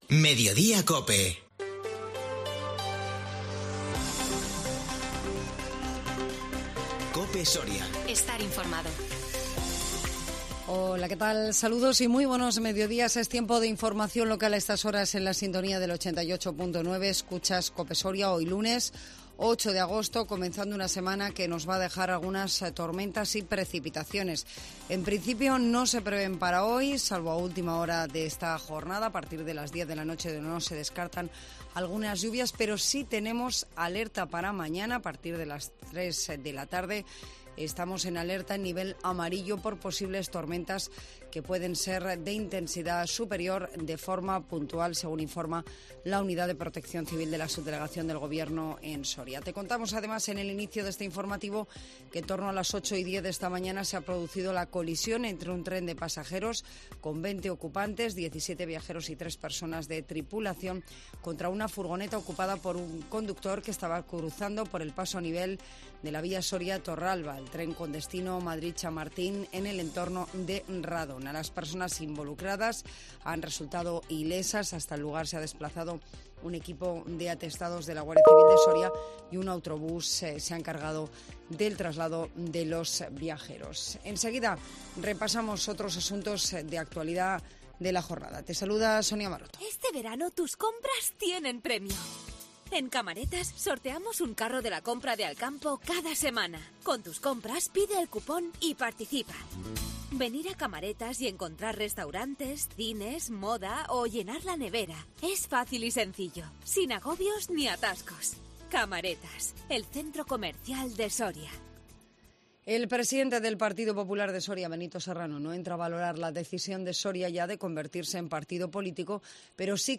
INFORMATIVO MEDIODÍA COPE SORIA 8 AGOSTO 2022